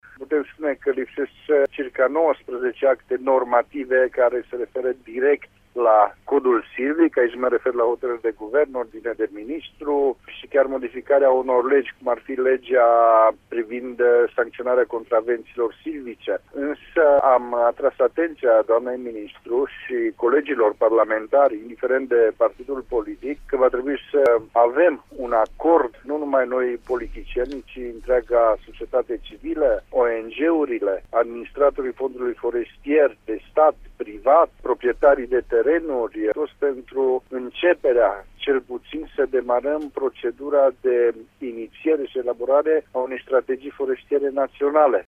Este opinia deputatul PNL Cristian Chirteș, membru al Comisiei pentru agricultură, silvicultură, indistrie alimentară și servicii specific din Camera Deputaților: